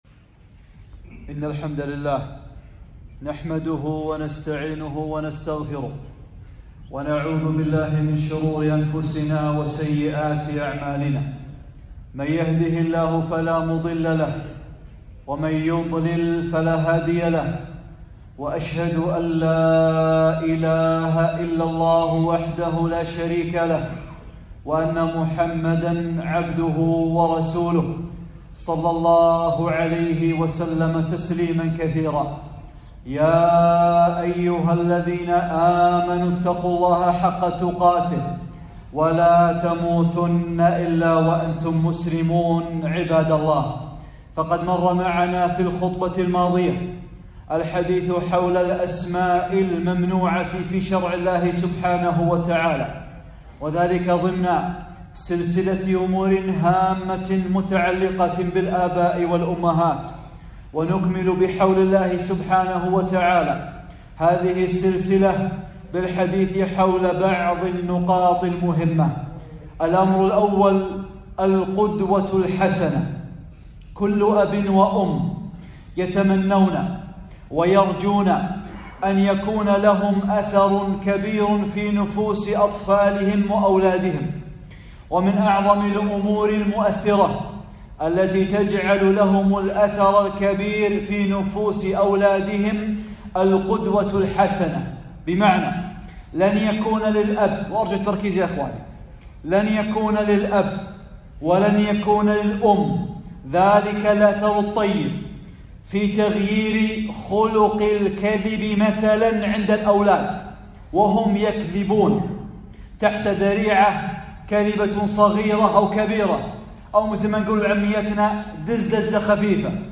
(17) خطبة - القدوة العملية - أمور هامة متعلقة بالآباء والأمهات